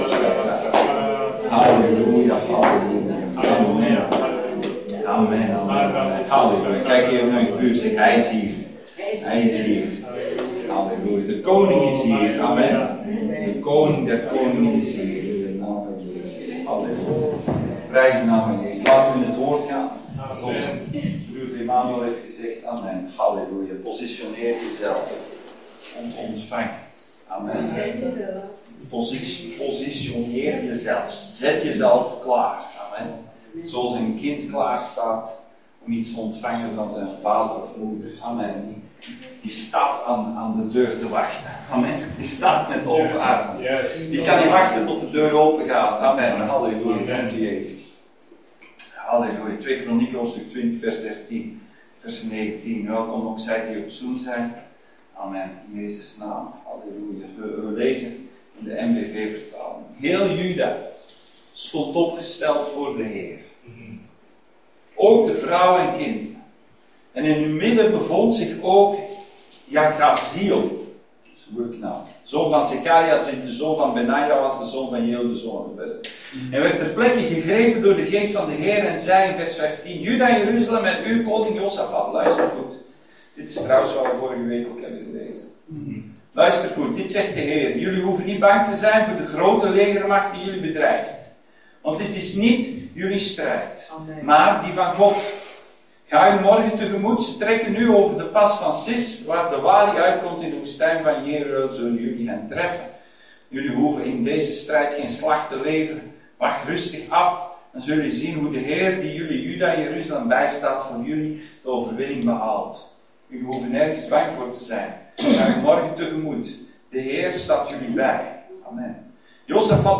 Josafat Dienstsoort: Zondag Dienst « Boek van Hebreeën